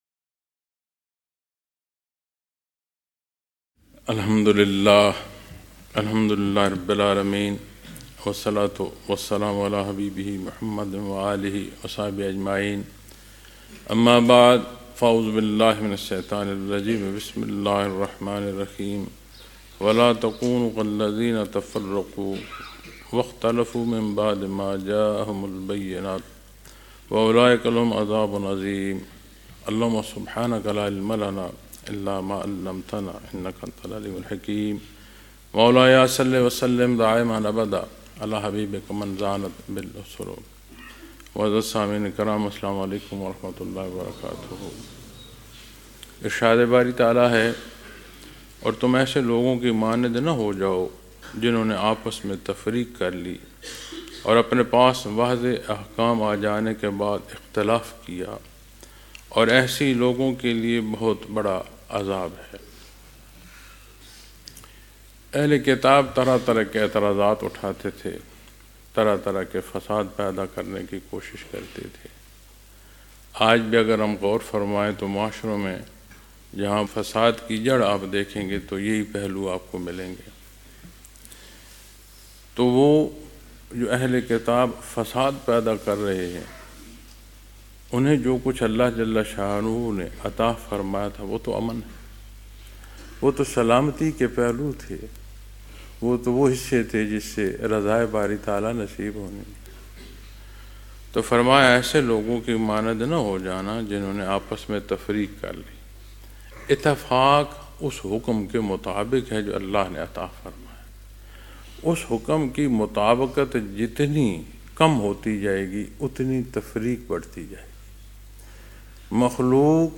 Lectures in Munara, Chakwal, Pakistan on January 4,2026